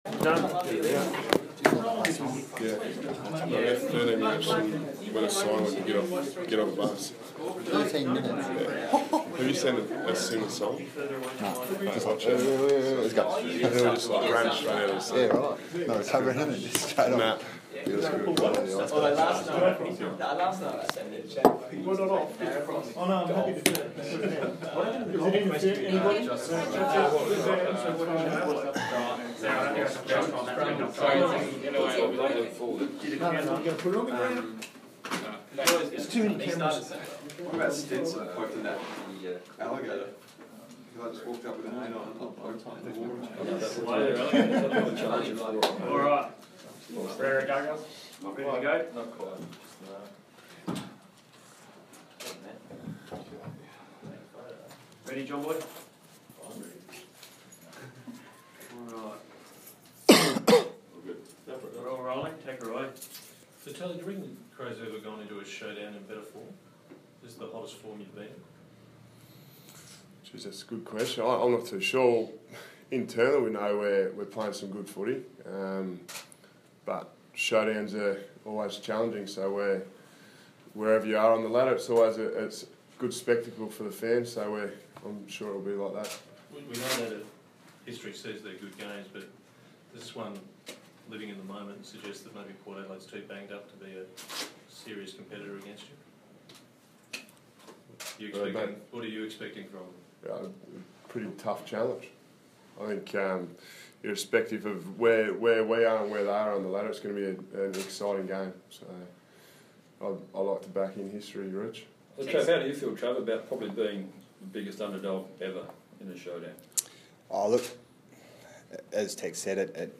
Showdown Captains' press conference - Monday, 16 August, 2016.
Port Adelaide Captain Travis Boak and Adelaide Captain Taylor Walker talk with media ahead of Saturday's Showdown XLI.